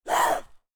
femalezombie_chase_01.ogg